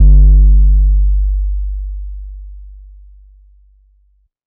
SOUTHSIDE_808_flavorr_G.wav